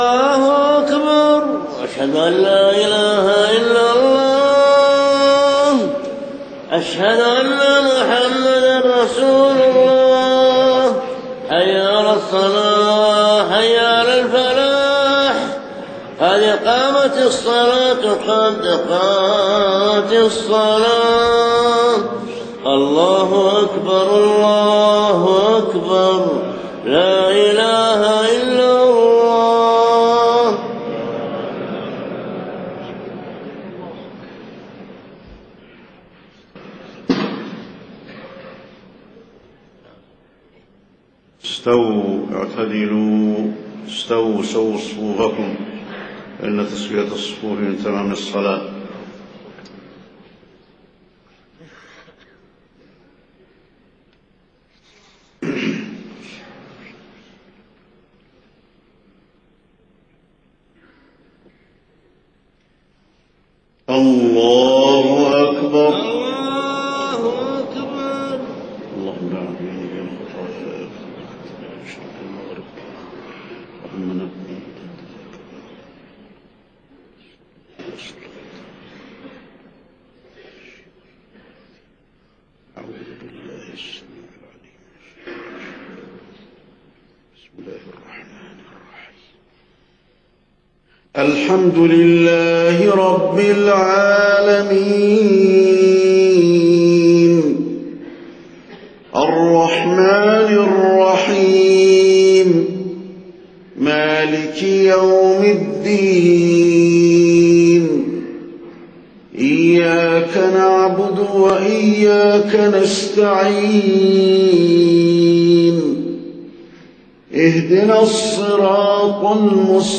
صلاة الفجر يوم عرفة 1429هـ سورة الحاقة كاملة > 1429 🕌 > الفروض - تلاوات الحرمين